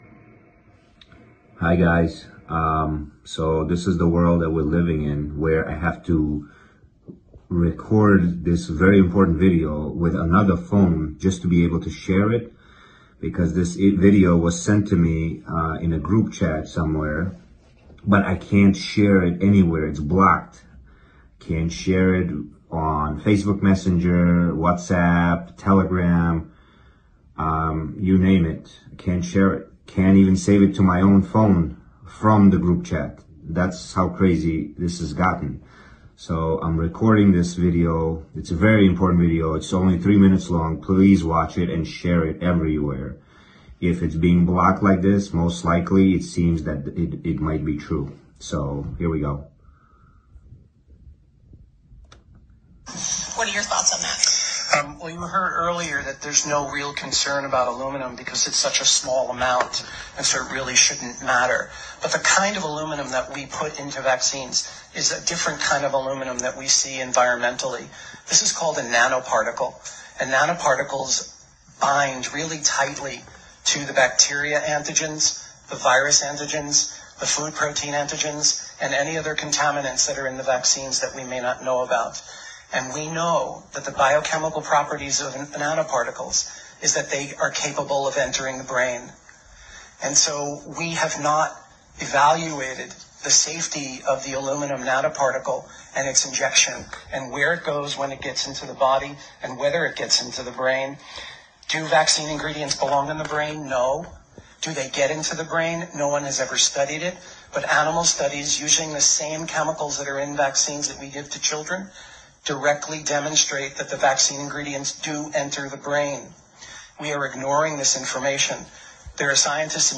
עדות חלקית ומאוד חשובה מפרוטוקל ישיבה אודות ההשפעות של נאנו חלקיקים של אלומיניום אשר מוחדרות דרך החיסונים אל מוחותיהם של המחוסנים. כפי הנראה זה קרה בארהב, הסרטון הזה נחסם משיתופים והבחור נאלץ לצלם אותו מנייד אחר על מנת לשתף.